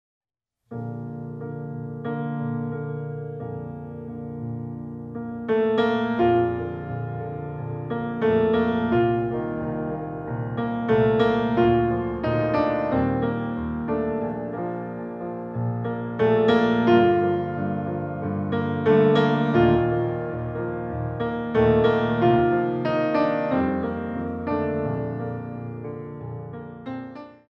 Variation